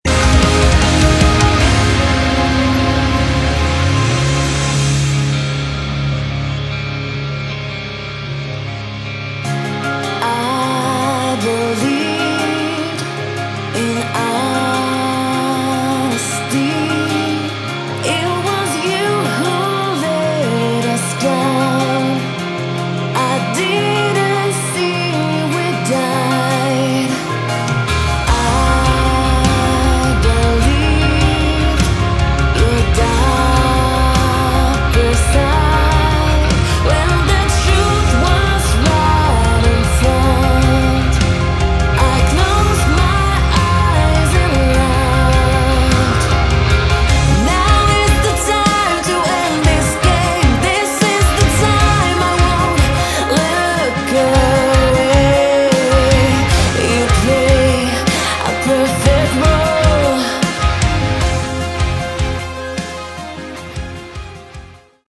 Category: Melodic Metal
vocals
guitars
keyboards
bass
drums